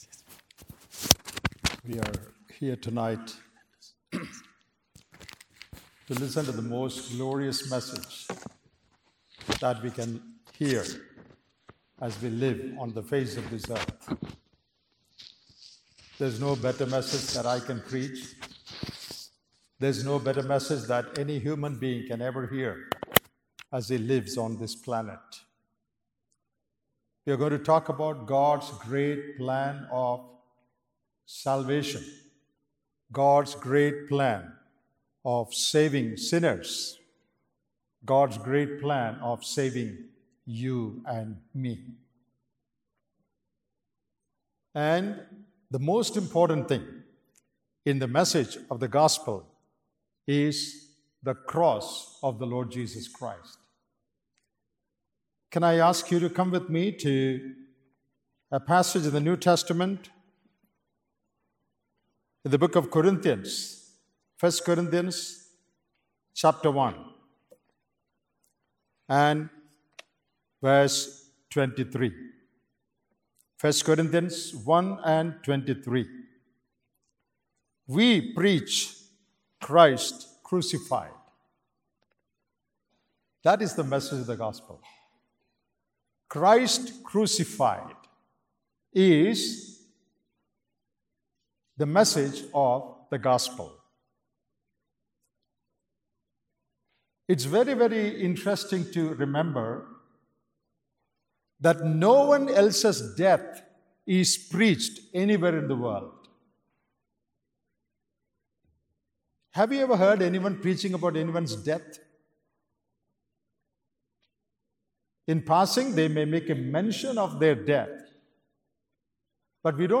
Listen Gospel message From Series
" IBF Conference 2025 " IBF 2025 Family Conference Facebook Tweet Link Share Link Send Email